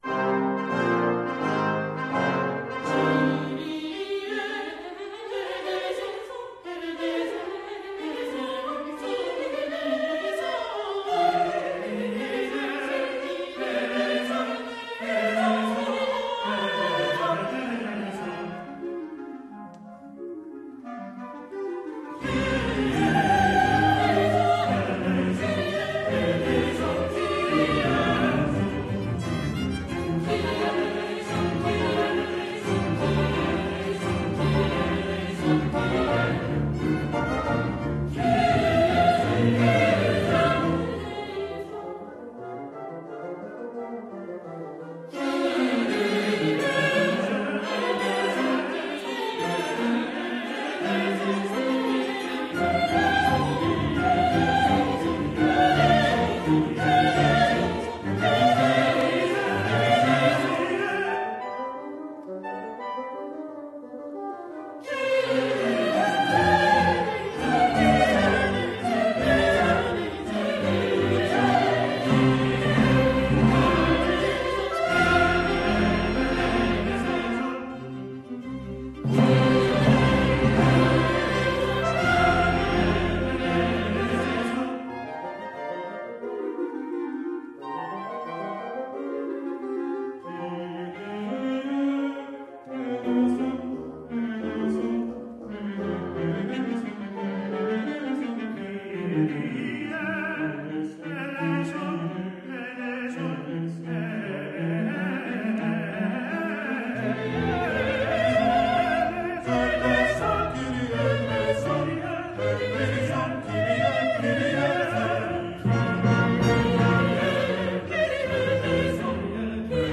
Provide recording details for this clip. Professional Recordings of performances